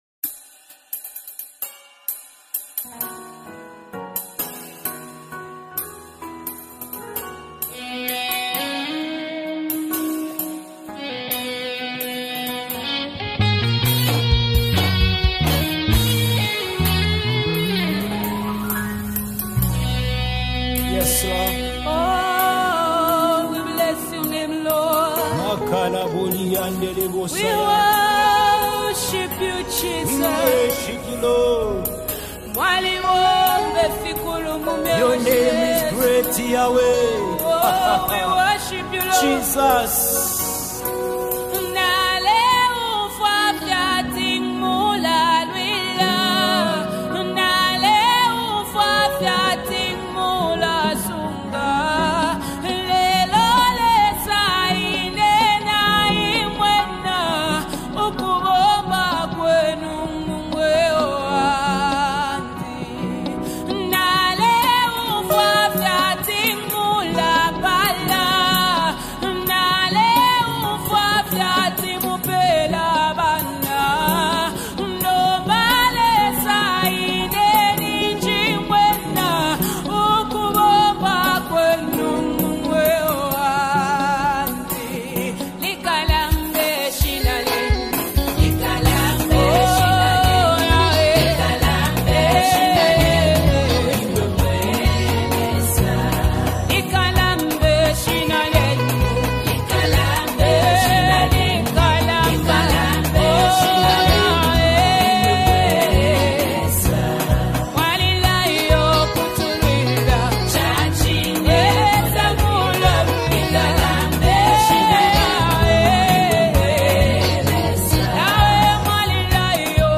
" worship song to God's majesty and sovereignty.